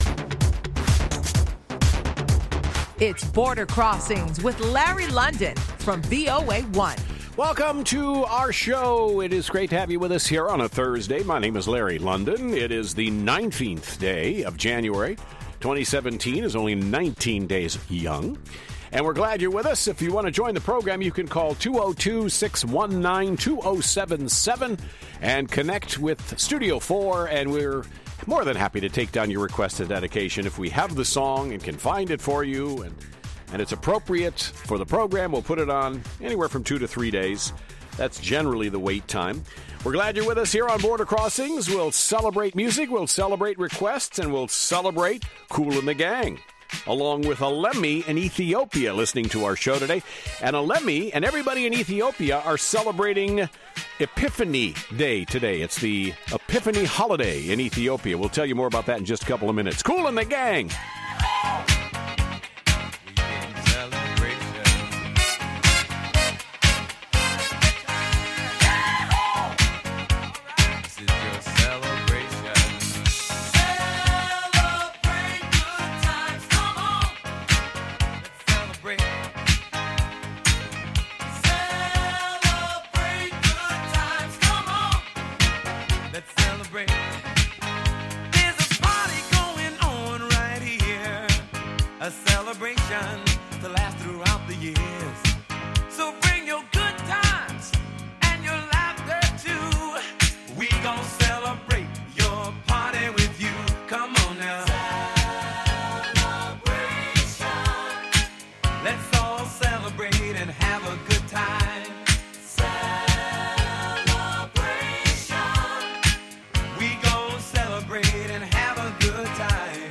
live worldwide international music request show